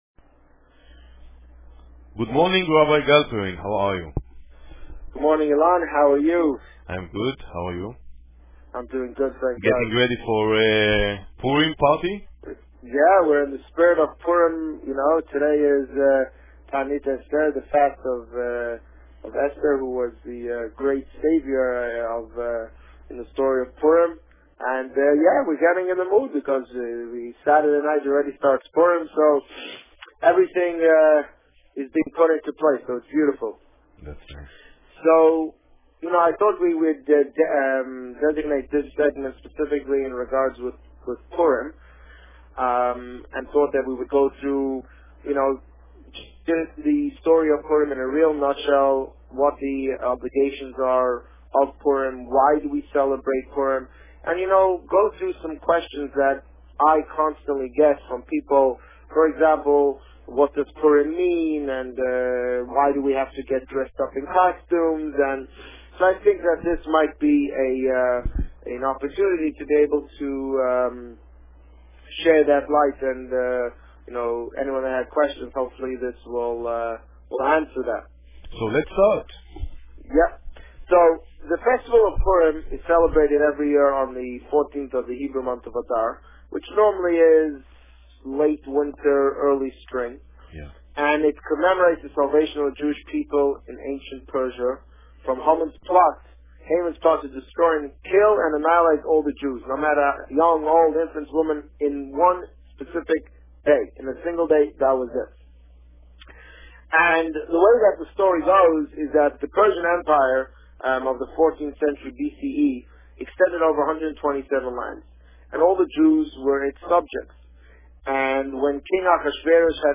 The Rabbi on Radio
The meaning of Purim Published: 17 March 2011 | Written by Administrator On March 17, 2011, the Rabbi spoke about the Fast of Esther, the meaning of Purim and the upcoming Purim festivities. Listen to the interview here .